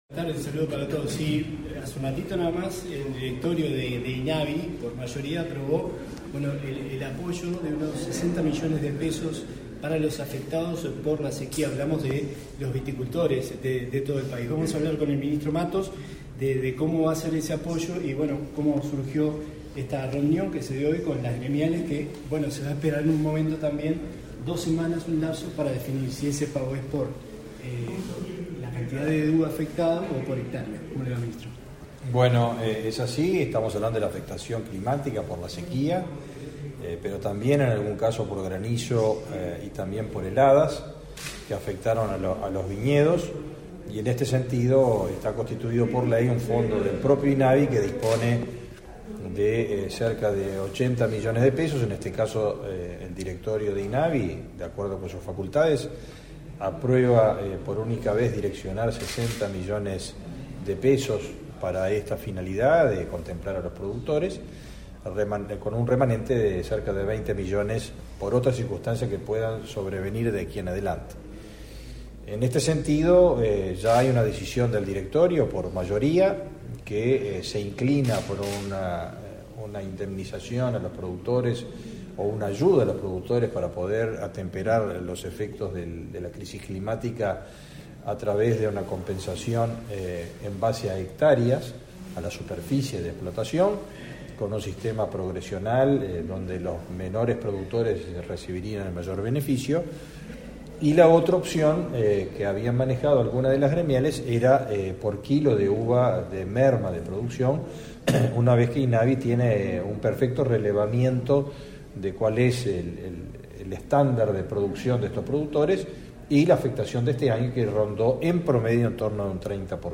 Declaraciones a la prensa del ministro de Ganadería, Fernando Mattos